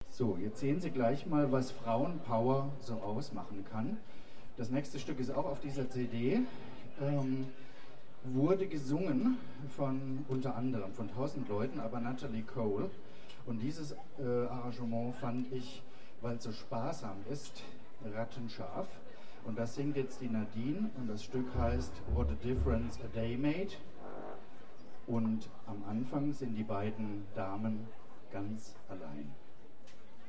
07 - Ansage.mp3